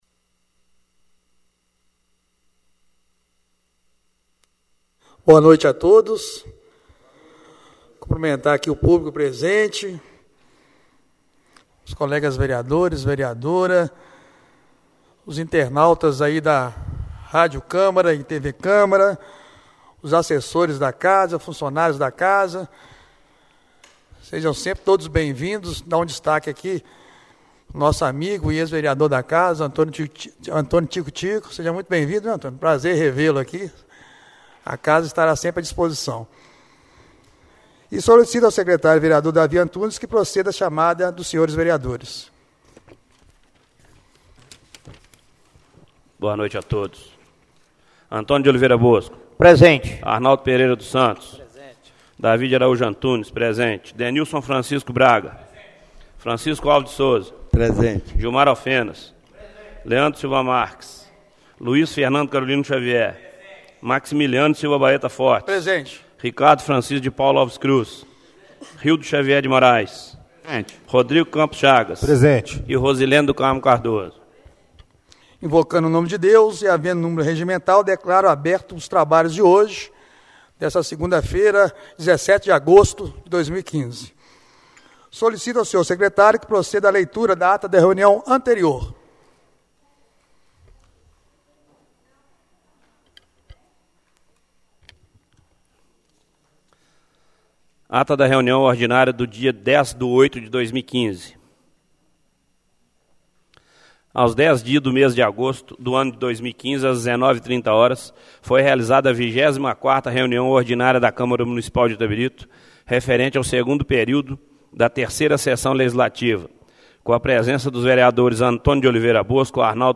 Reunião Ordinária do dia 17/08/2015